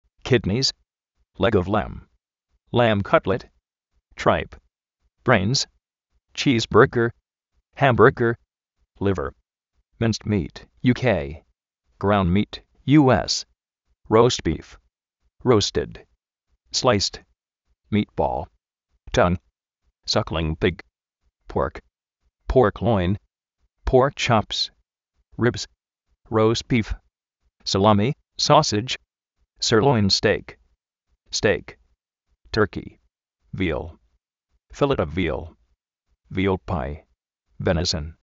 kídnis
chí:s-bérguer